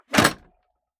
Ammo Crate Close 002.wav